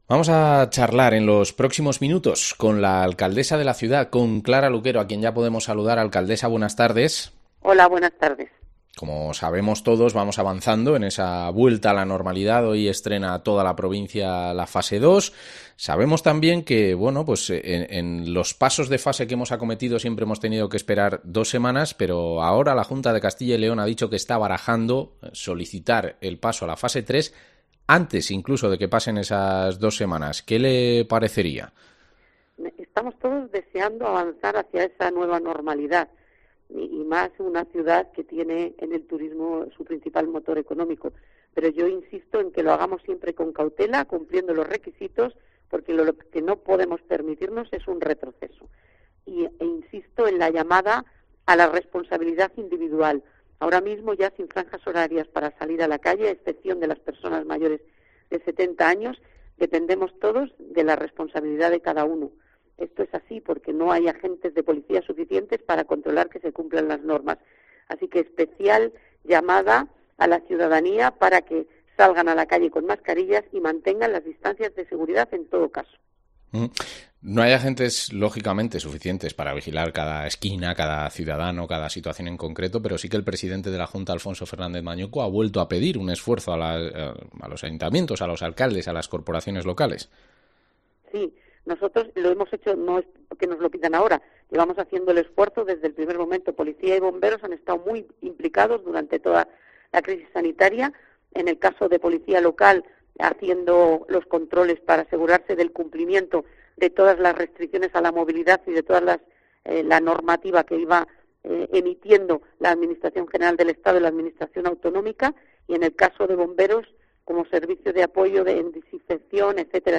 Entrevista a la alcaldesa de Segovia, Clara Luquero